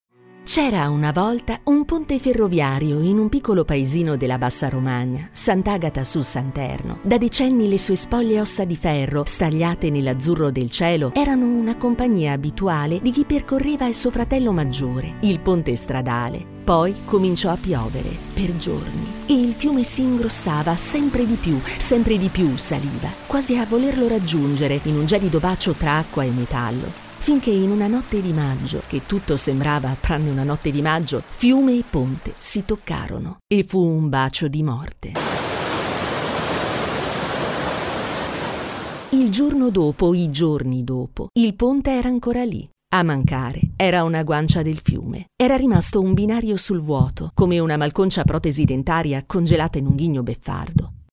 Her articulate, engaging delivery suits commercials, narration, e-learning, and character work – perfect for brands seeking a professional Italian voice actor.
Narration
Mic Neumann TLM103